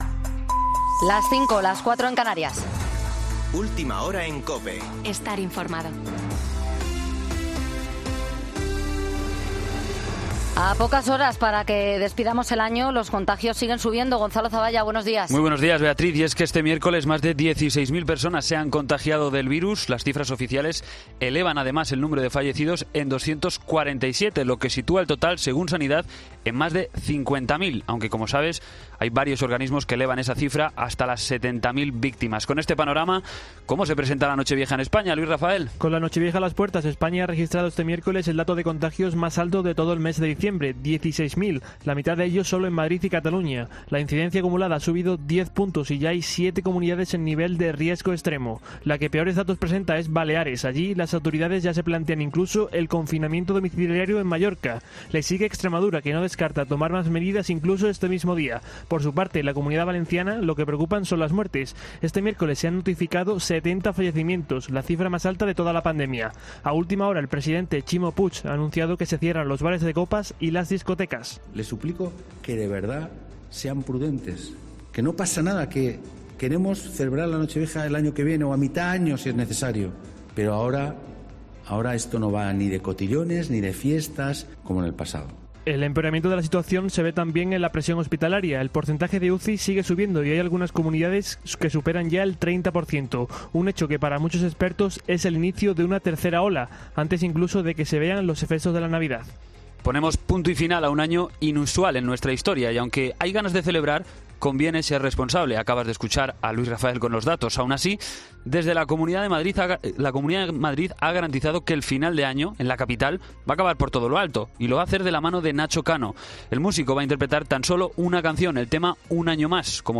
AUDIO: Boletín de noticias COPE del 31 de diciembre de 2020 a las 05.00 horas